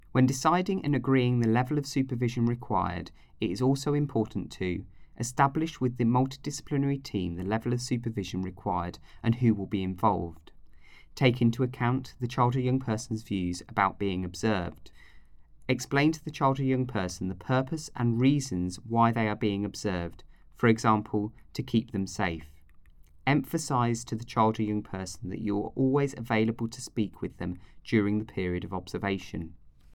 Biographical account read by actor